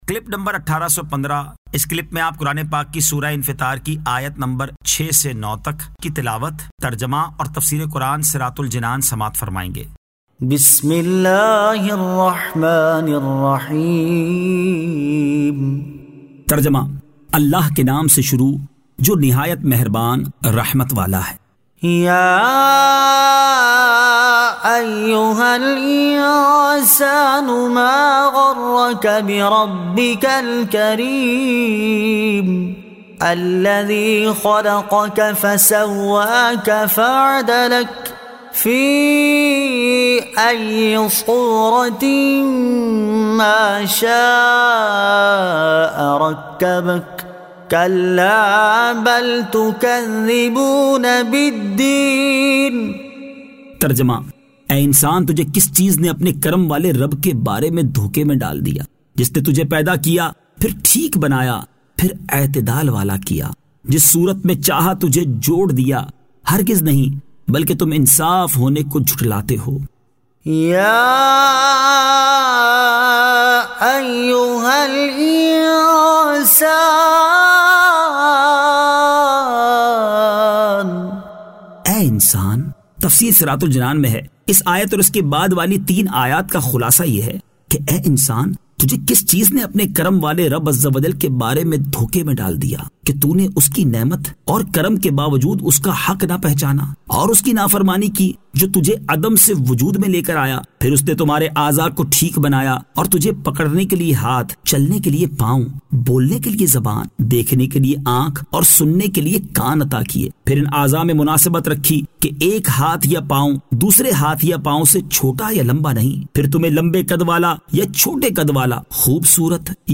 Surah Al-Infitar 06 To 09 Tilawat , Tarjama , Tafseer